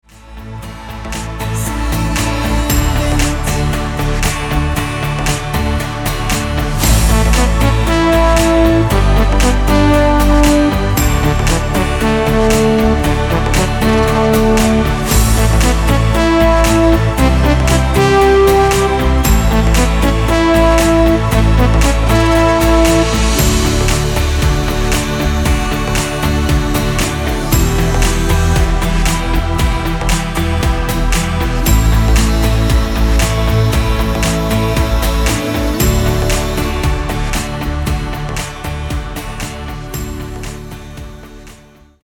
Tonart: D Dur Karaoke Version mit Chor